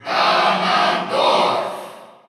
Category: Crowd cheers (SSBU) You cannot overwrite this file.
Ganondorf_Cheer_Russian_SSBU.ogg.mp3